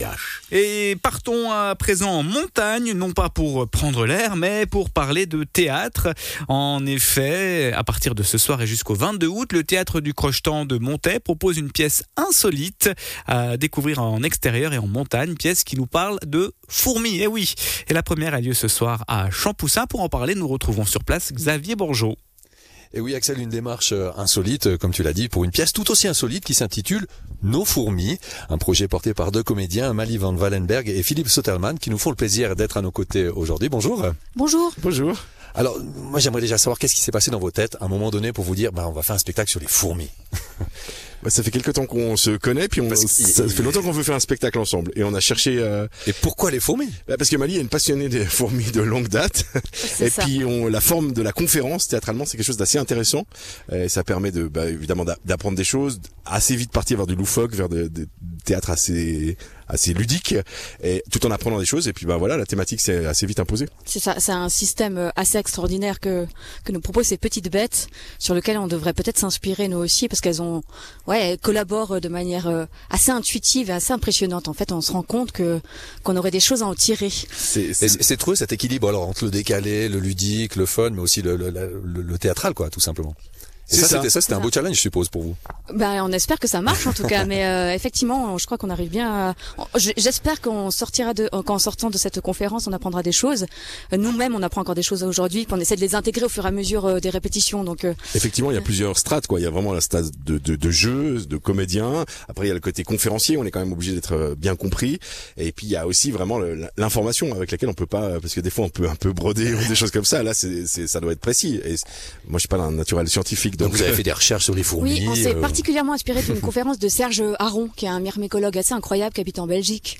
comédienne
comédien